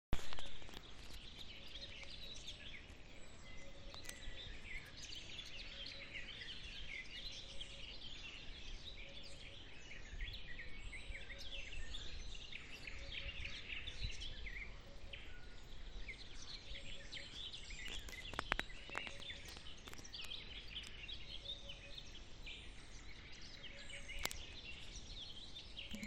Hoopoe, Upupa epops
StatusVoice, calls heard